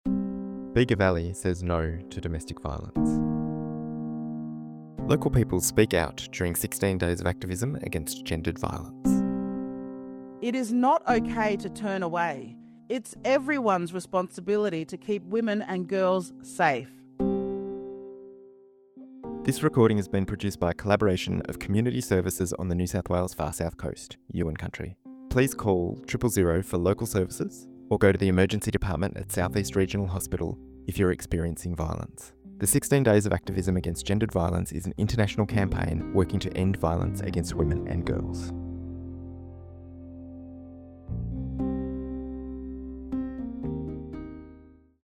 This year, the Bega Valley Domestic Violence & Sexual Assault Committee collected 25 statements from Bega Valley Community members, men and women from all walks of life, calling on all of us to do our part to stop Gender-Based Violence.
As part of this campaign, we collected brief audio statements from local Bega Valley community members to raise awareness about domestic, family, and sexual violence.